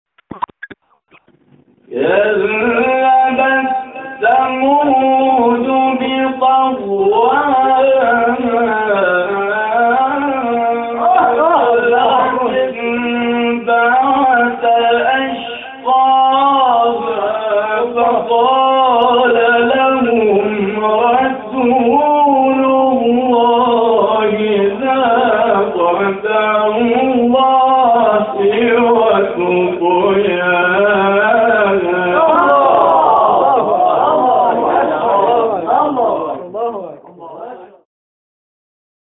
به گزارش خبرگزاری بین‌المللی قرآن(ایکنا) مقاطع صوتی از تلاوت قاریان بین‌المللی و ممتاز کشور که به تازگی در شبکه‌های اجتماعی منتشر شده است، ارائه می‌شود.
فرازی از تلاوت